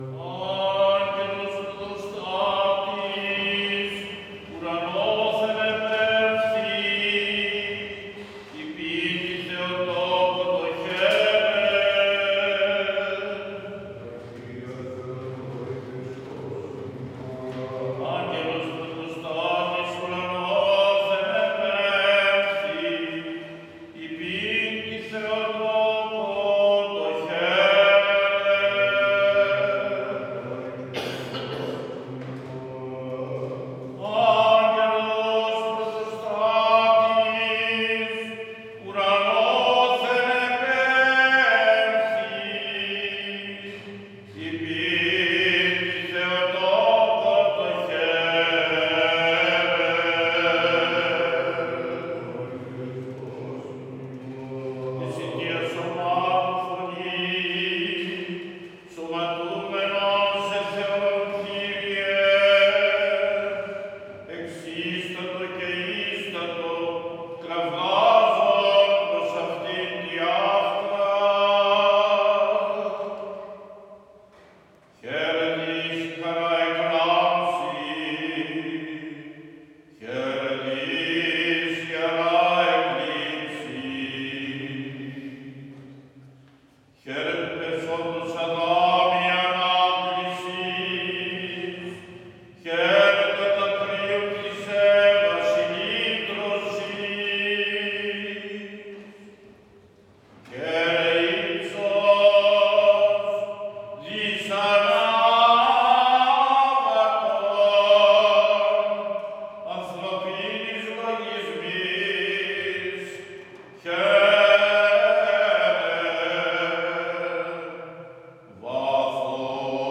17.04.2021 Σάββατο τοῦ Ἀκαθίστου - ΙΕΡΑ ΜΟΝΗ ΞΕΝΟΦΩΝΤΟΣ